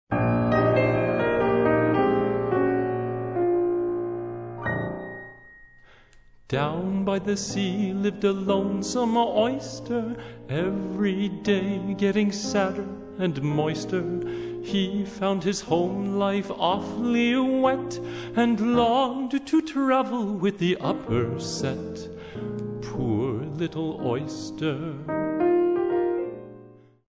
piano
Vocals